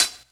Closed Hats
1 Hh -pony.wav